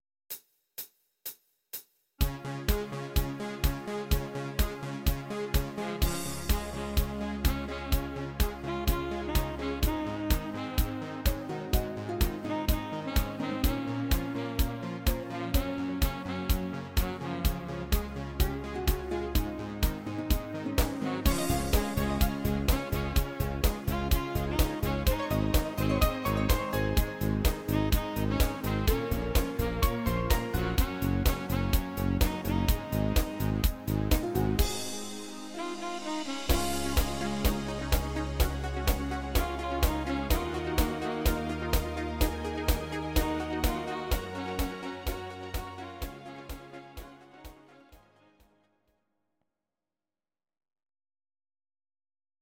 Audio Recordings based on Midi-files
Pop, German, 2010s